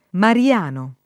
vai all'elenco alfabetico delle voci ingrandisci il carattere 100% rimpicciolisci il carattere stampa invia tramite posta elettronica codividi su Facebook mariano [ mari- # no o mar L# no ] agg. («di Maria Vergine») — es.: il mese mariano ; il culto mariano